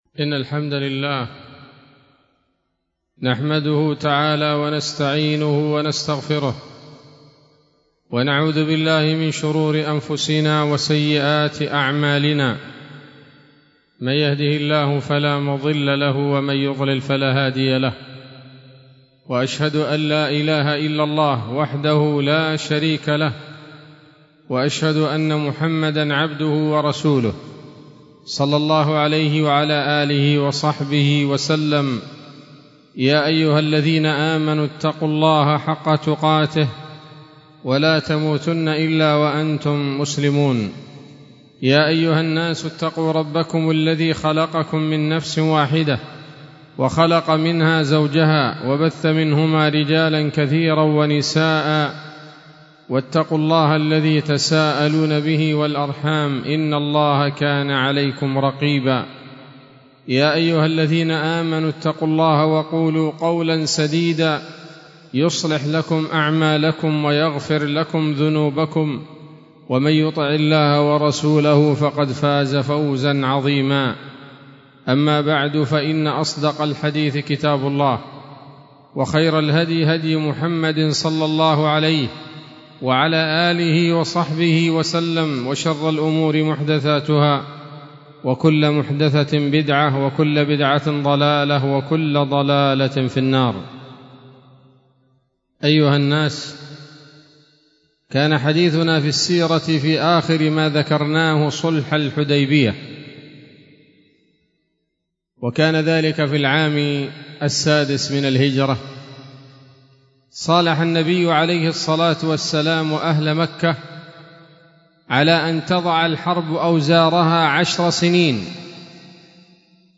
خطبة جمعة بعنوان: (( السيرة النبوية [26] )) 13 محرم 1446 هـ، دار الحديث السلفية بصلاح الدين